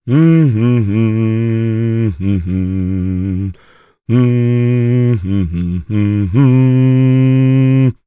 Gau-Vox_Hum_jp.wav